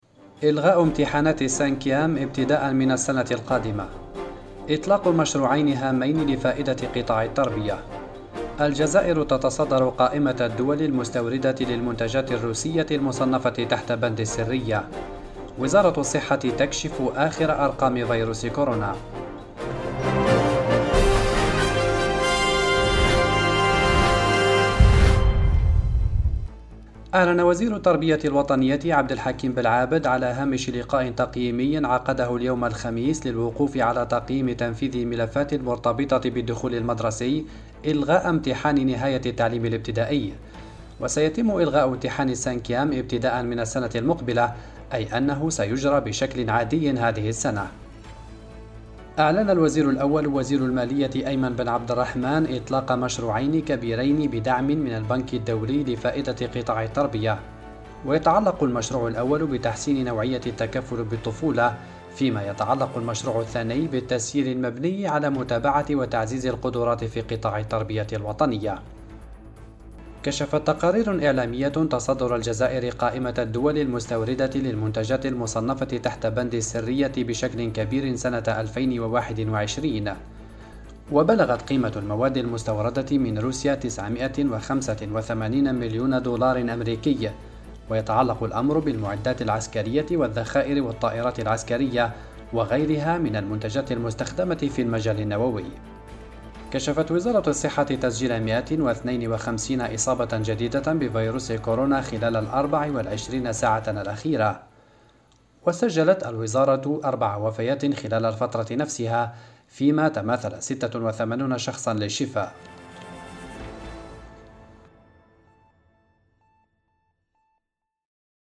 النشرة اليومية: إلغاء امتحانات “السانكيام” – أوراس
النشرة الرقميةفي دقيقتين